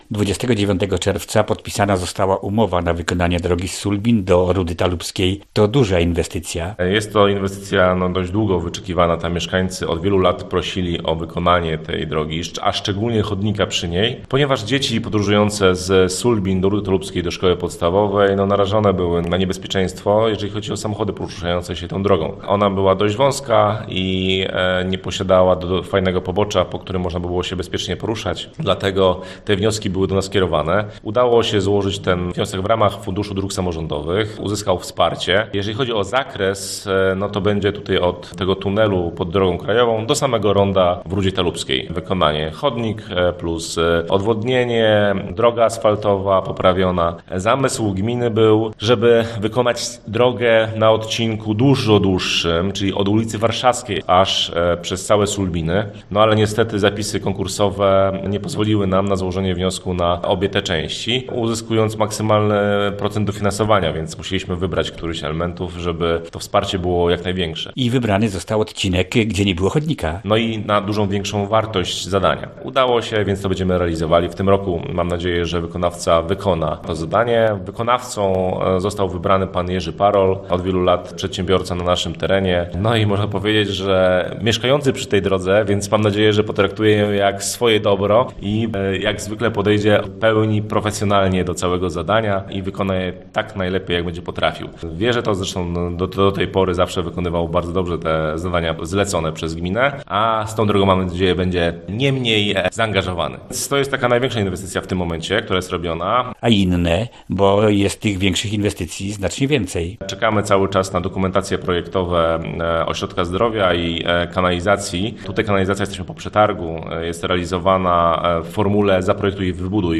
Więcej o realizowanych inwestycjach, z wójtem gminy Marcinem Kołodziejczykiem rozmawia